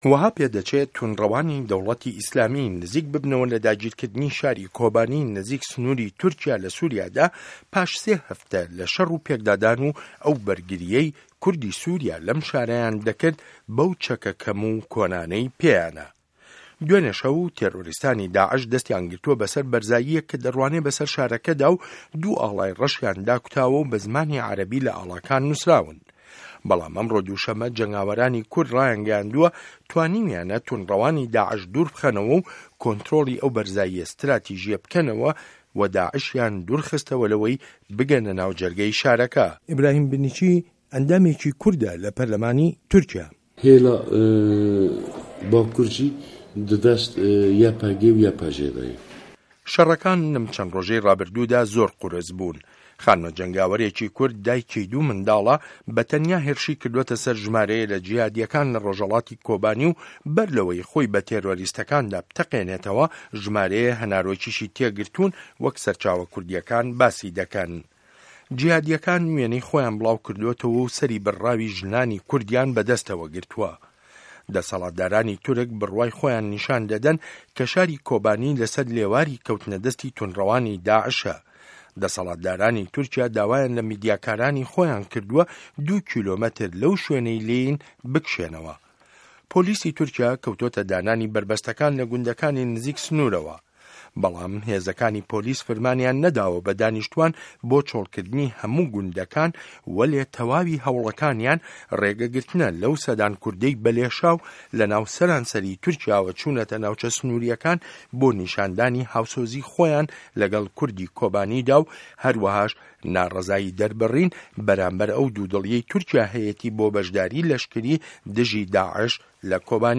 ڕاپـۆرتی کۆبانی